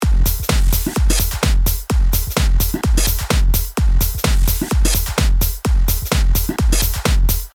• Tube ：真空管を通したときのような暖かみを持った歪みが加わる
ドライブレベル80％：キックが歪んでてやりすぎ感ありますね。